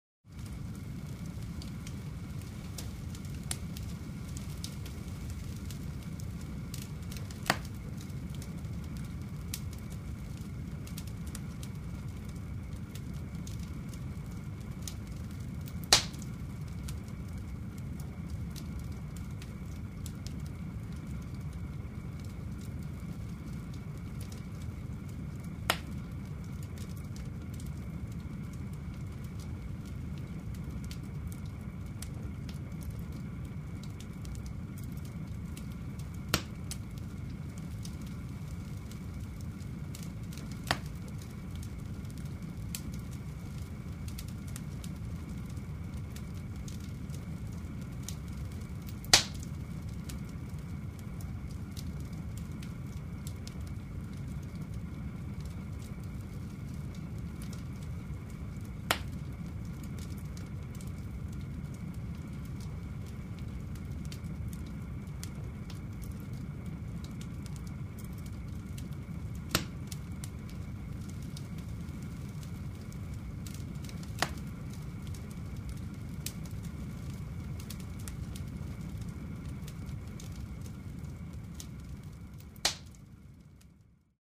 Подборка включает разные варианты: от спокойного потрескивания до яркого горения.
Шепот лесного костра в ночи